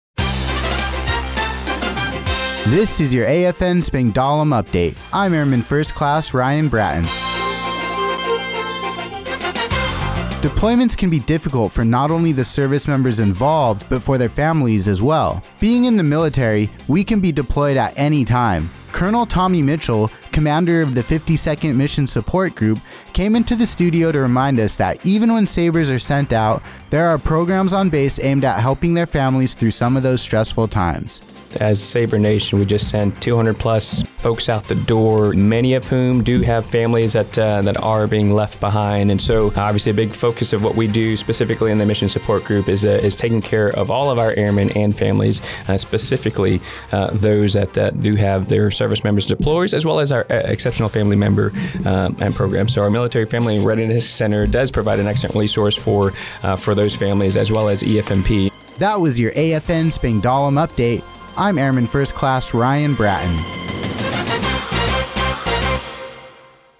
The following was the radio news report for AFN Spangdahlem for 241031.